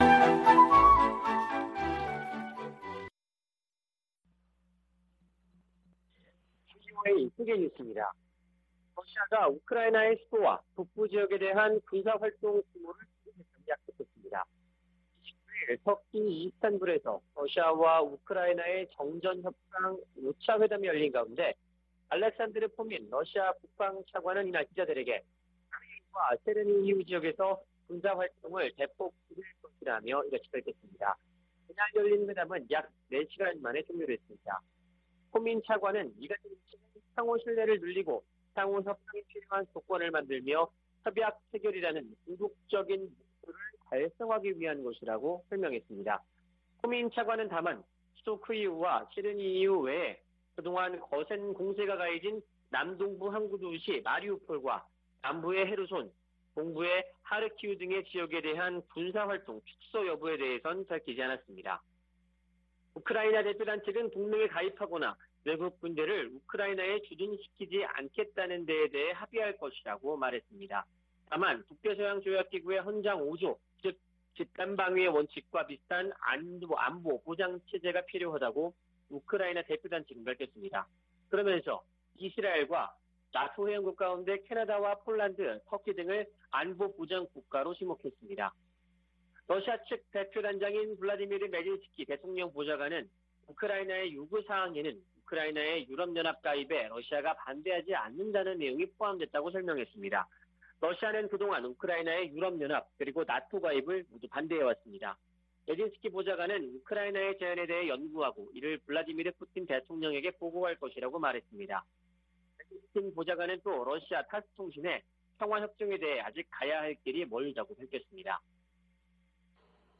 VOA 한국어 아침 뉴스 프로그램 '워싱턴 뉴스 광장' 2022년 3월 30일 방송입니다. 한국 국방부는 북한이 지난 24일 발사한 ICBM이 '화성-17형'이 아닌 '화성-15형'인 것으로 판단했습니다. 한국 함동참모본부는 미한일 세 나라가 하와이에서 합참의장회의를 개최한다고 밝혔습니다. 미국이 새 유엔 안보리 대북제재 결의안 채택을 추진하고 있지만, 실현 가능성이 낮다는 전망이 지배적입니다.